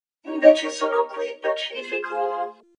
A vocoder was used on “pacifico” and then on “politica”, you can hear pre and post-treatment here.